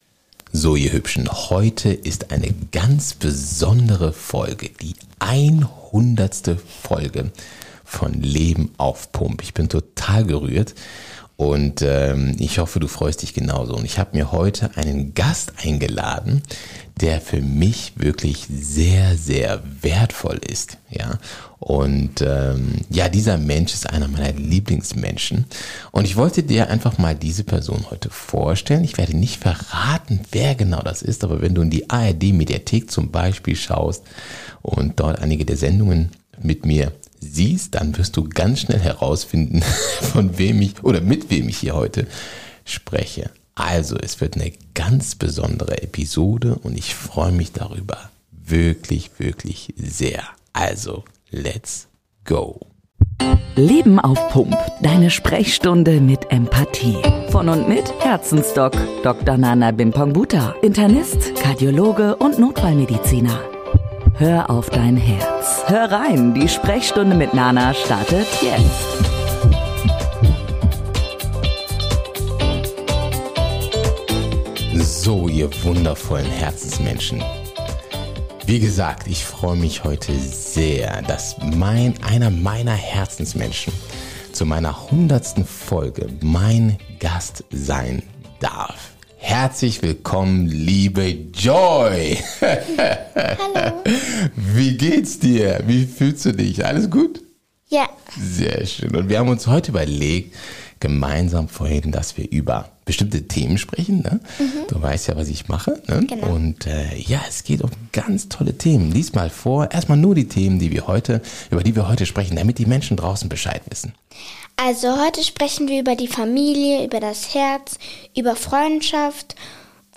Leiser.
In dieser Episode spreche ich mit einem meiner persönlichen Lieblingsmenschen 🥹❤❤ In einem offenen, ehrlichen Gespräch – frei, ungestellt, von Herz zu Herz.
Sie ist ein Gespräch.